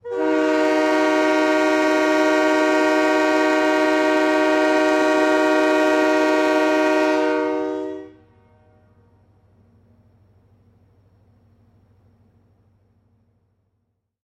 Звуки тепловоза
Звук протяжного гудка локомотива